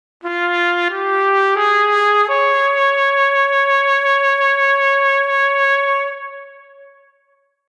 Les prochains extraits audio que vous entendrez dans ce billet seront tous agrémentés de cette réverbe.
Et d’autre part, on va pouvoir régler la vitesse du vibrato avec le CC19 :
Vibrato-vitesse.mp3